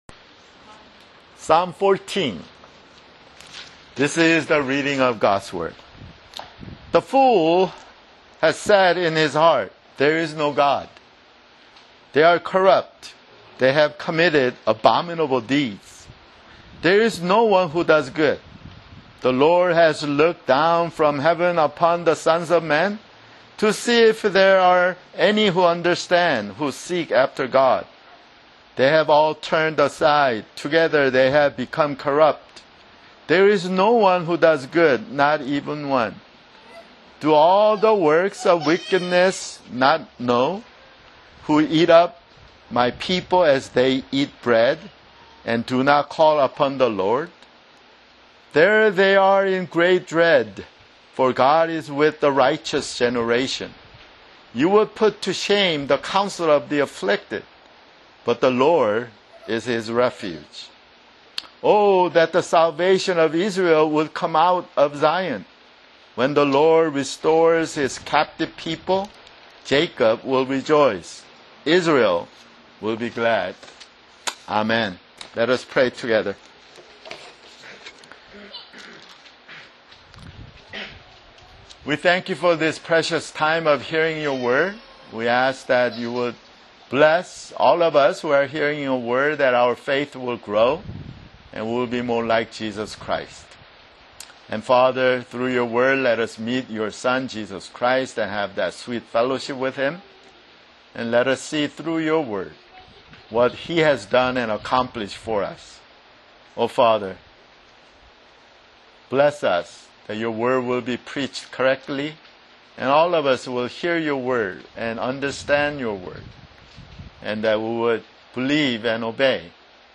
[Sermon] Psalms (12)